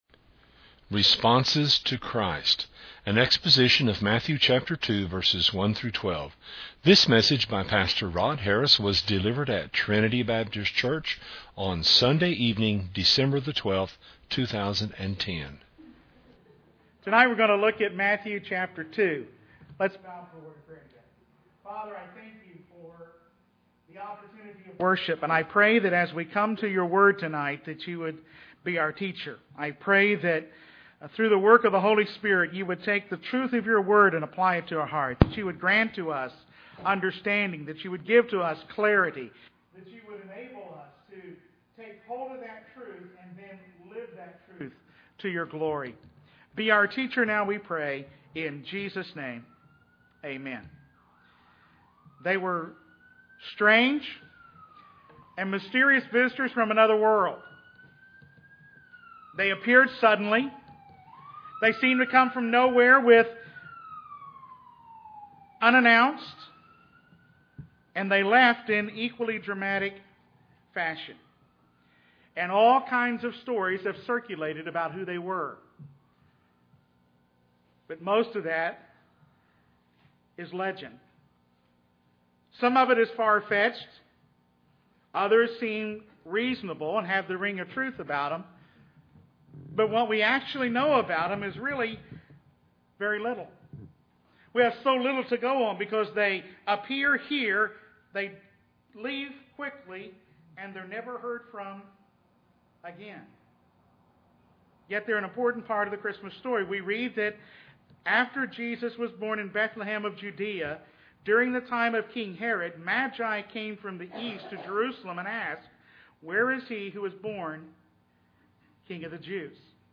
An exposition of Matthew 2:1-12.